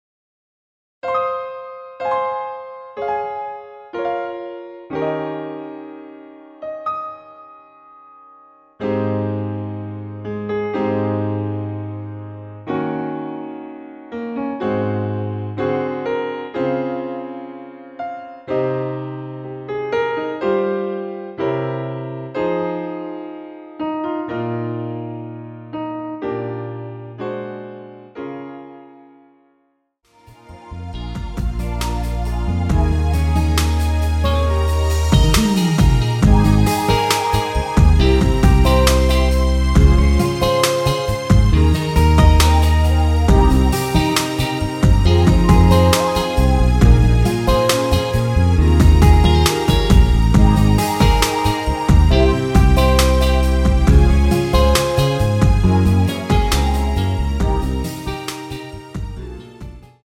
원키에서(+3)올린 MR입니다.
Ab
앞부분30초, 뒷부분30초씩 편집해서 올려 드리고 있습니다.